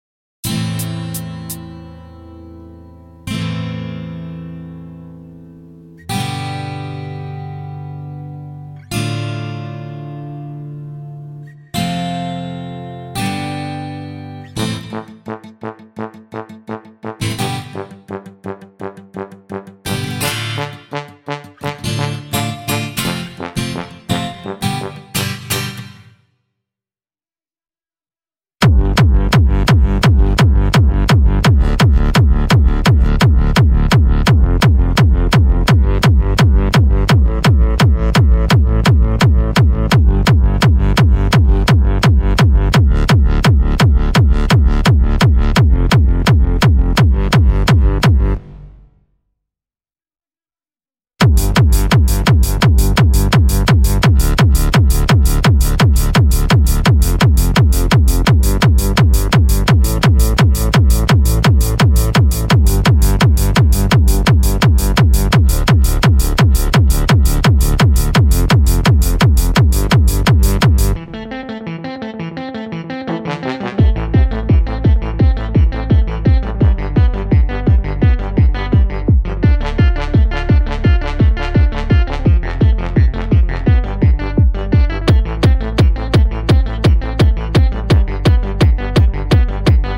Zonder backing